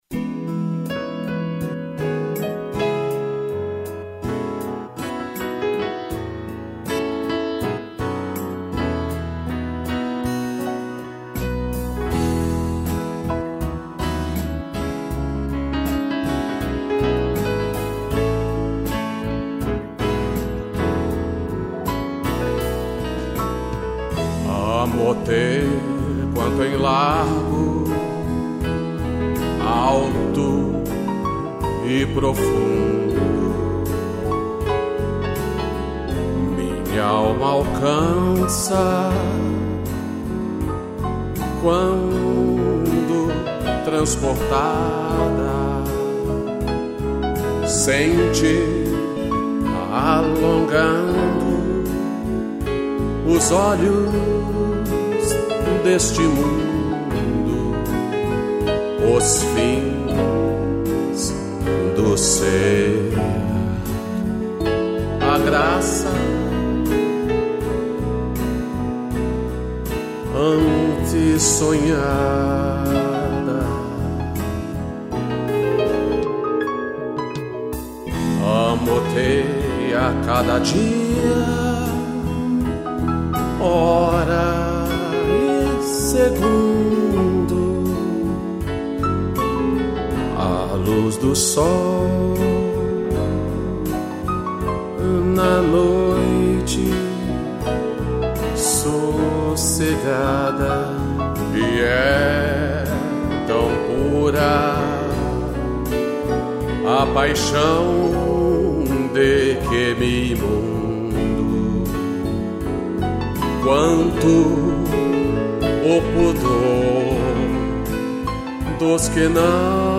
pianos